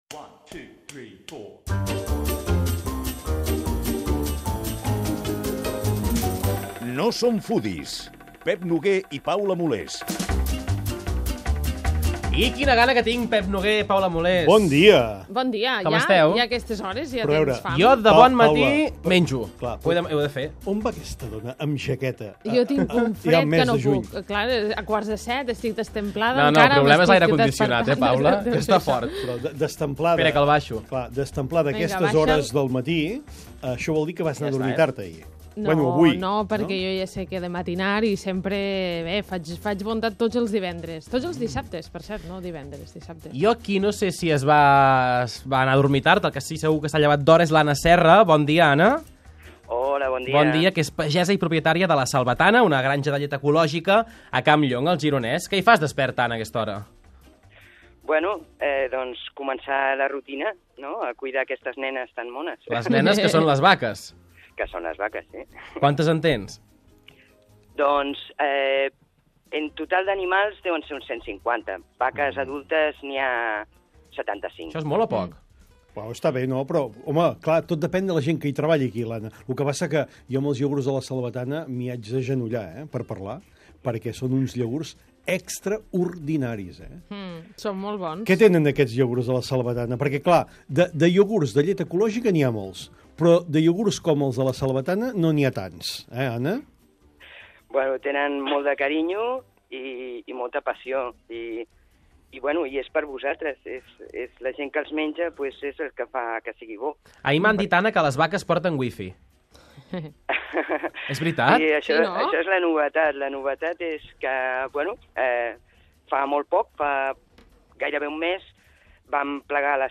Expliquem quina és la història dels restaurants xinesos a casa nostra, quin va ser el primer i com han anat evolucionant. Parlem amb dos cuiners xinesos amb restaurants a Barcelona per saber quina és la veritable gastronomia del país.